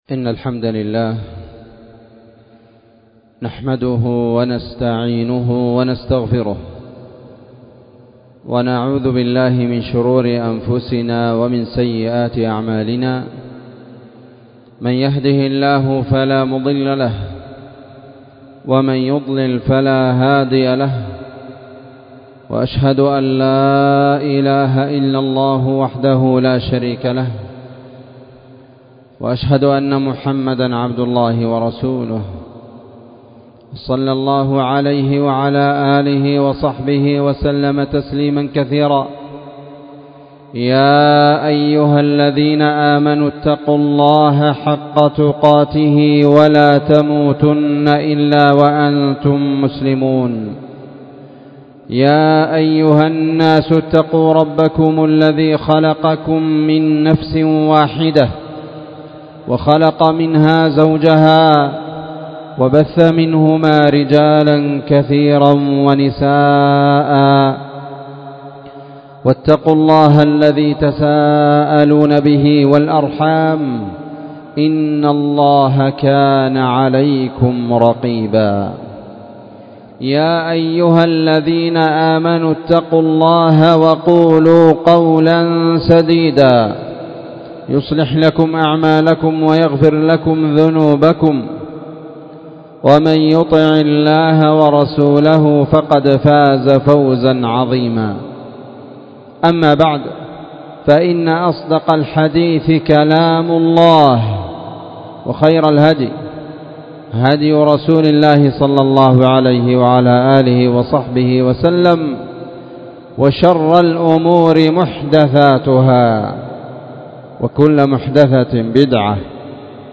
خطبة
في مسجد المجاهد- النسيرية- تعز